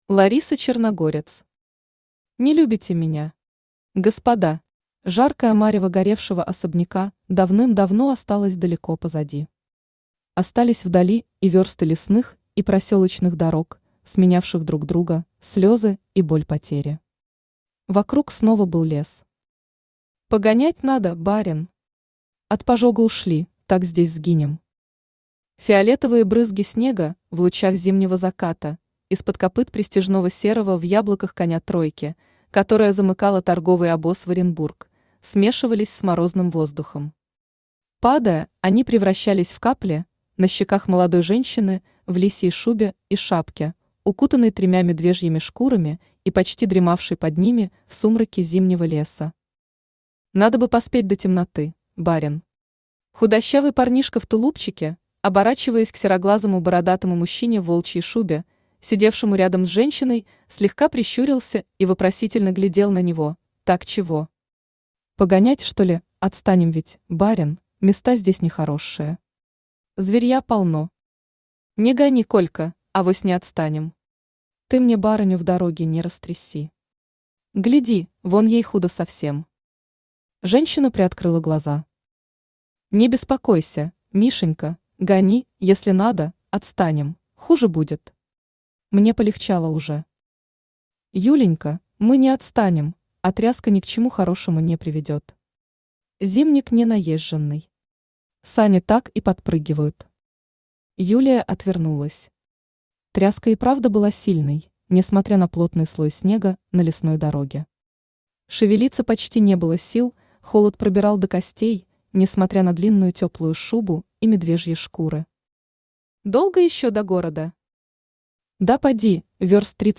Аудиокнига Не любите меня! Господа!
Автор Лариса Черногорец Читает аудиокнигу Авточтец ЛитРес.